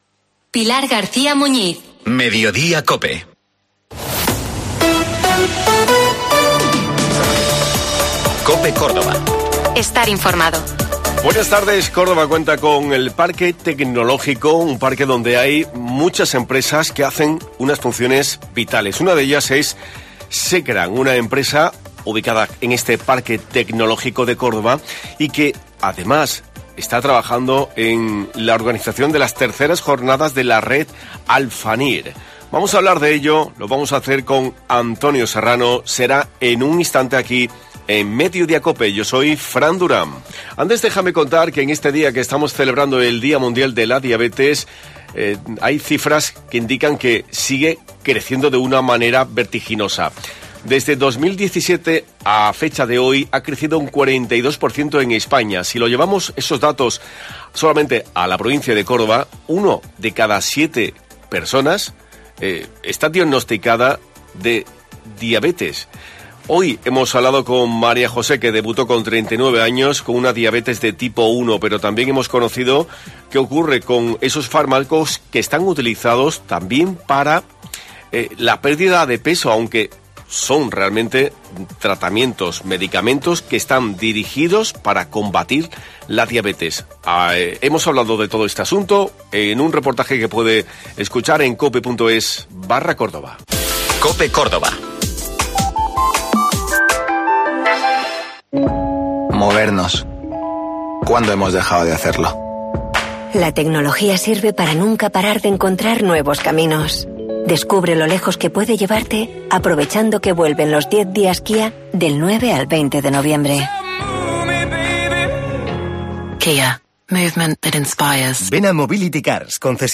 El próximo 23 de noviembre tendrá lugar la III Jornada de la Red Alfanir. Hemos hablado con la empresa encargada de su organización, ubicada en el Parque Tecnológico de Córdoba, llamada SECRAN.